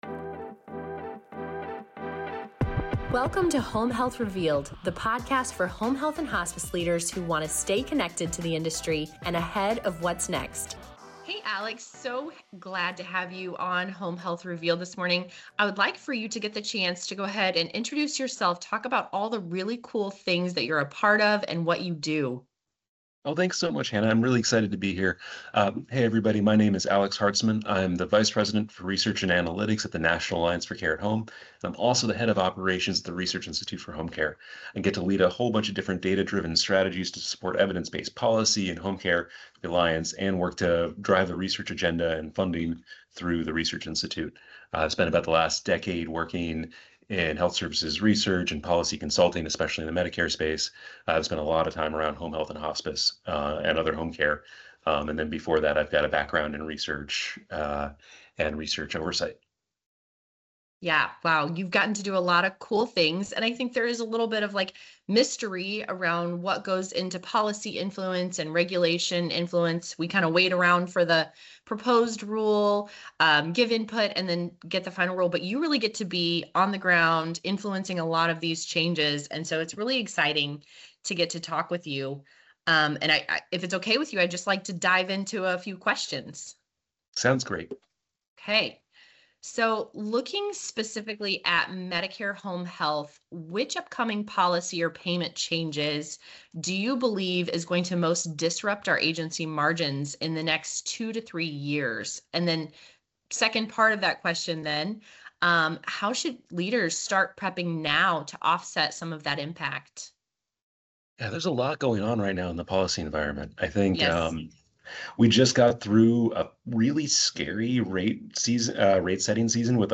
They explore the ongoing pressure from Medicare reimbursement changes, the impact of value-based purchasing and quality metrics, and the growing workforce challenges affecting agencies across the country. The conversation also dives into the increasing demand for home-based care, access-to-care concerns, and why the story of home health is not always reaching policymakers the way it should.